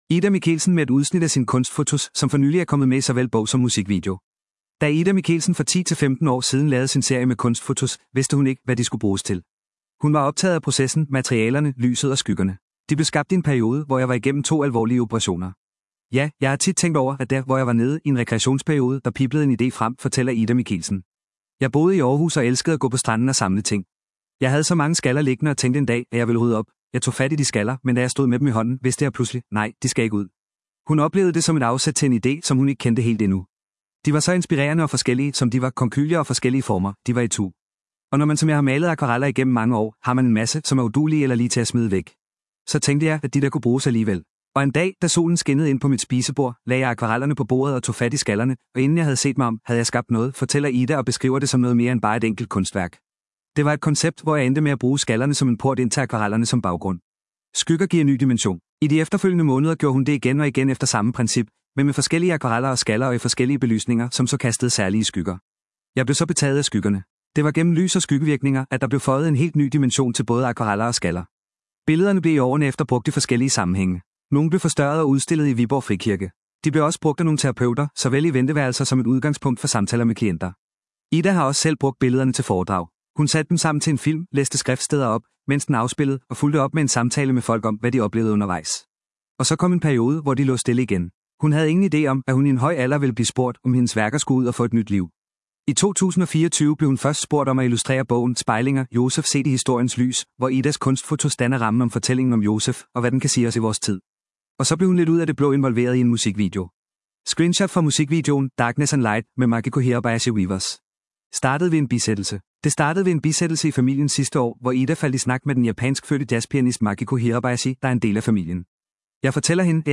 japanskfødte jazzpianist